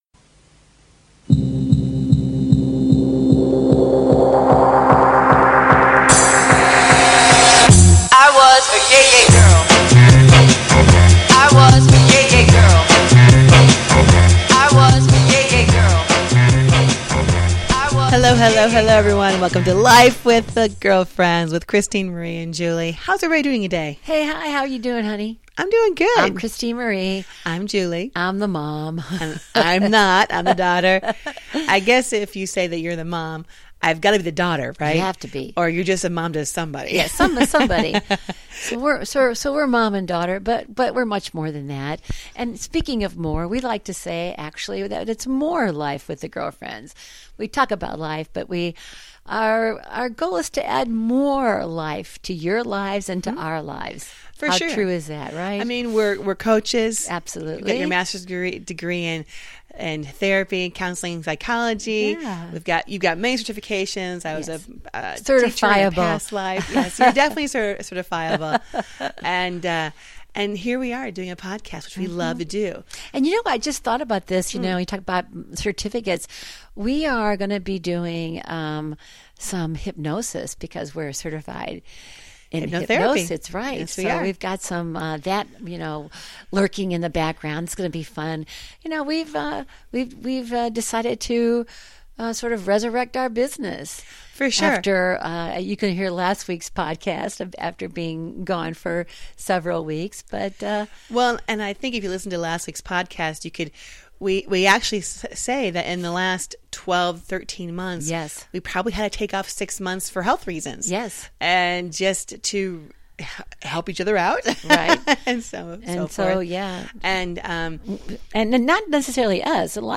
They welcome a wide range of guest to their den for some juicy conversation.
And join the girlfriends up close and personal for some daily chat that’s humorous, wholesome, and heartfelt.